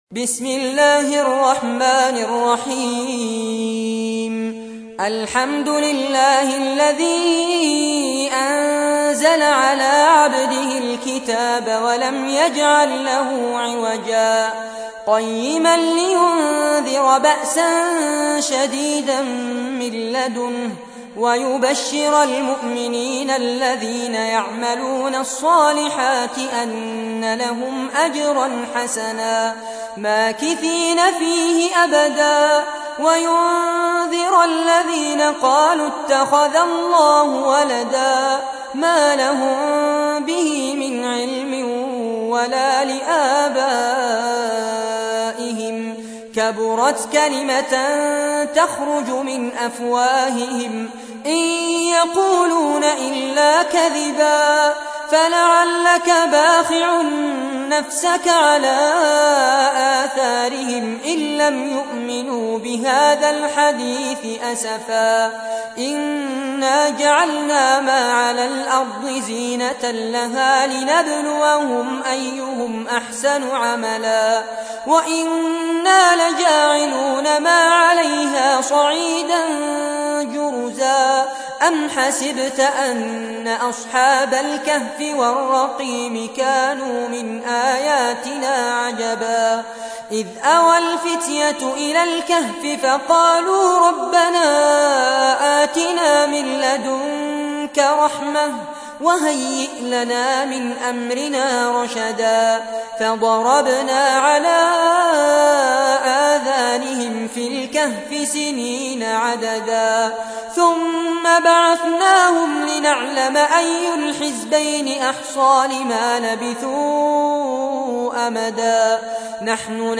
تحميل : 18. سورة الكهف / القارئ فارس عباد / القرآن الكريم / موقع يا حسين